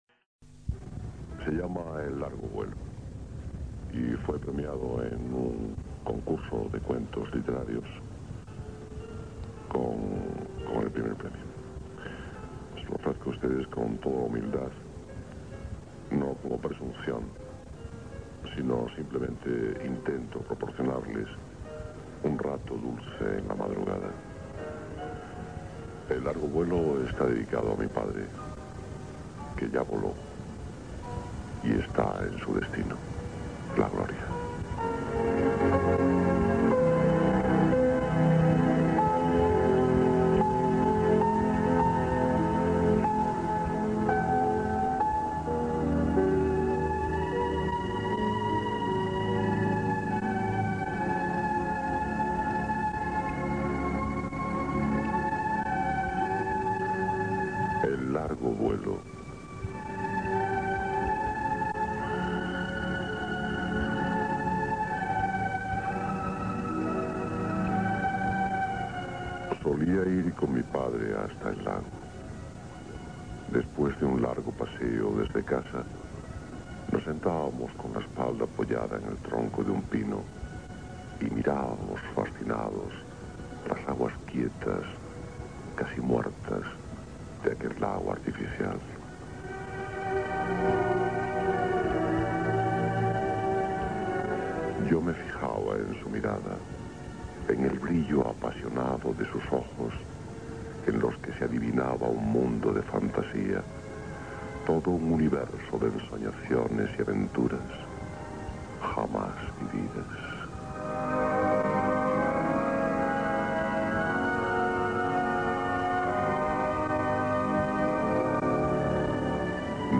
Para recordarlo que mejor que con su voz disfrutar de uno de sus relatos, en este caso uno muy especial, "El Largo Vuelo" un cuento que Alés había escrito y dedicado a su padre y que además ganó el Primer Premio de Cuentos Literarios. El propio Antonio José, narró con emoción "El Largo Vuelo" a través de los micrófonos de Radio España-Cadena Ibérica en su Medianoche el 29 de Diciembre de 1989.